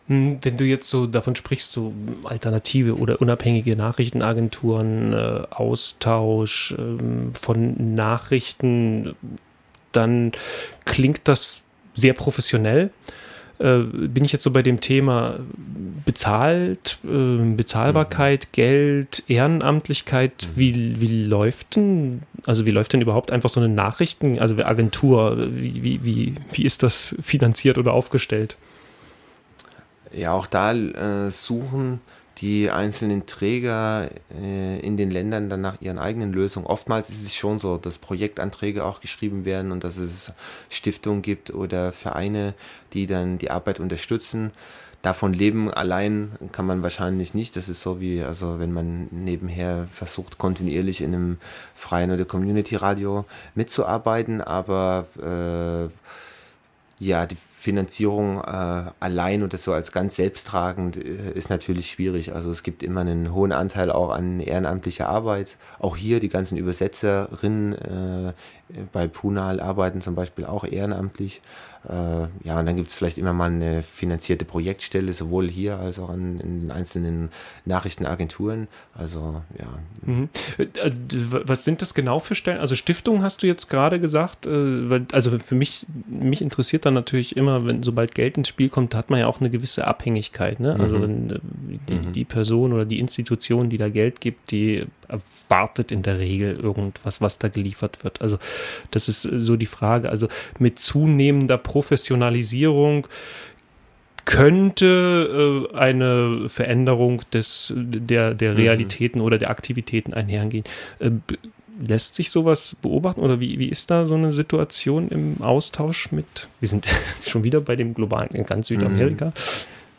Radiointerview Am 12.